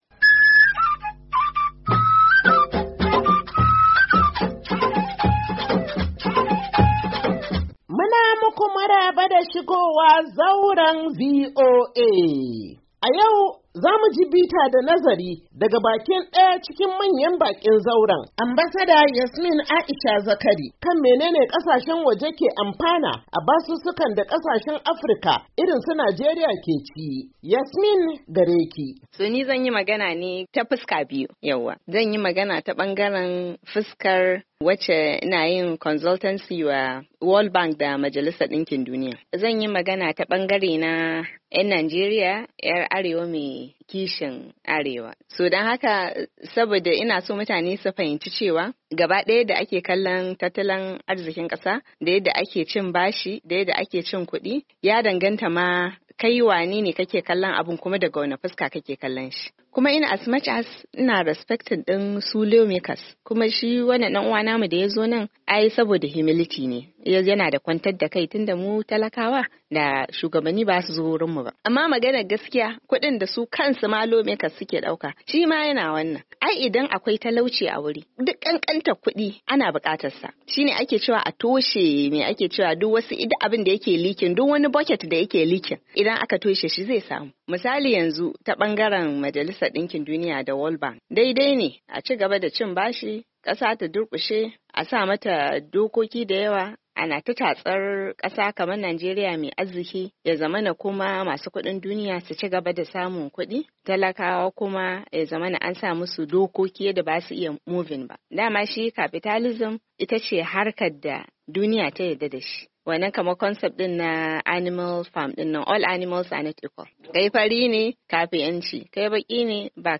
Shirin Zauran VOA na wannan makon, zai kawo muku ci gaba da tattaunawa kan yadda Najeriya ke karbo bashi, inda Ambasada Yasmin Aisha Zakari ta yi fashin baki kan abin da cin bashi ya ke nufi ga manyan Hukumomin Majalisar Dinkin Duniya da kuma Bankin Duniya, idan ya shafi kasashen Afirka.